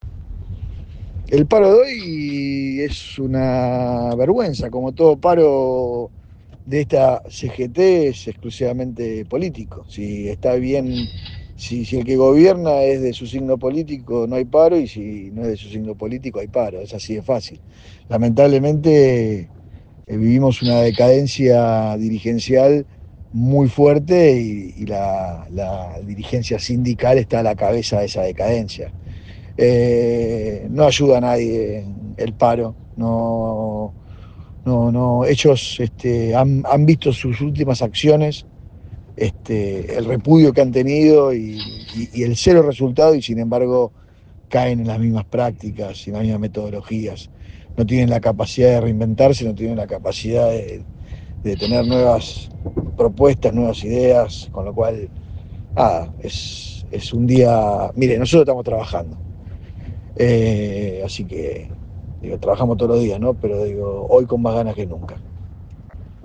Desde Argentina Política, conversamos con diferentes actores afines al gobierno Nacional.
Sebastián Pareja, dirigente más importante dentro del armado de LLA PBA